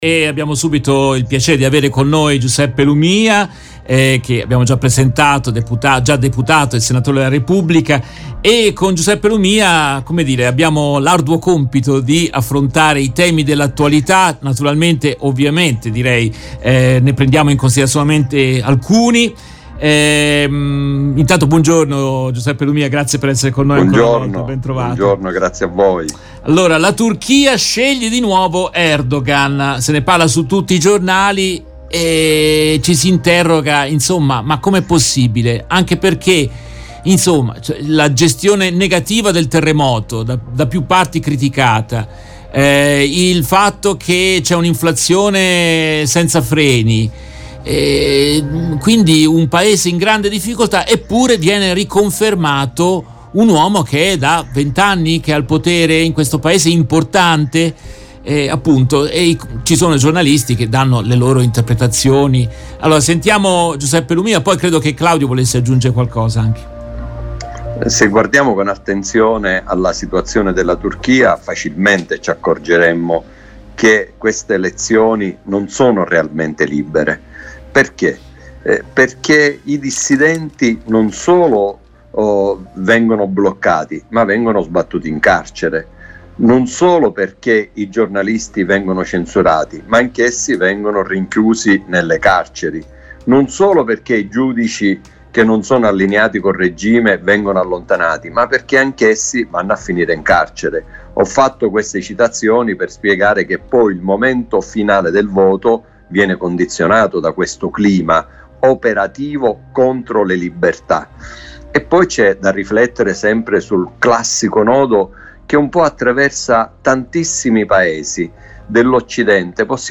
Nel corso della trasmissione in diretta del 29 maggio 2023